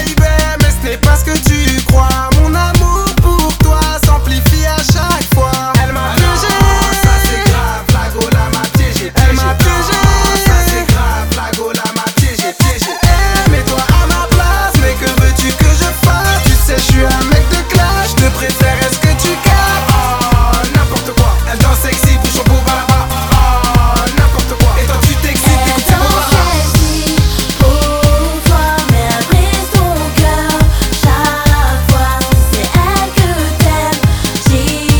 Жанр: Поп музыка / Танцевальные / Электроника